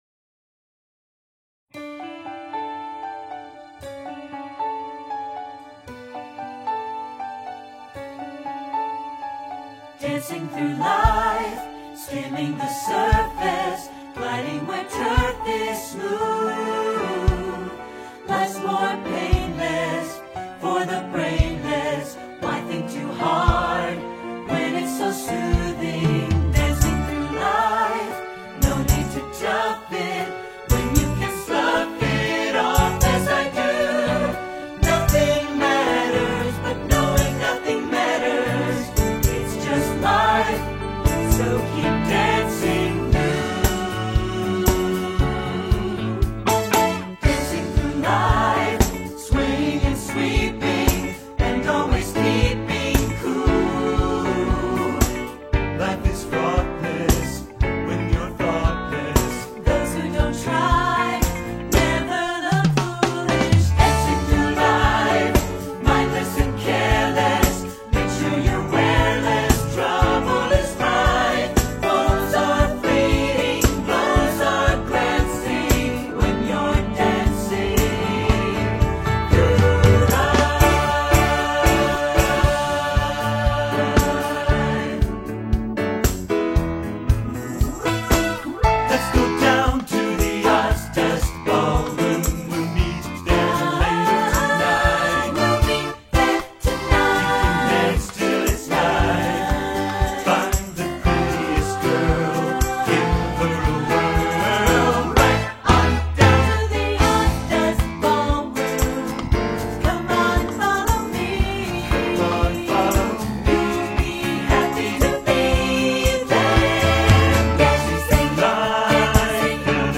Dancing through Life - Full Perf (audio L & video R)Full Performance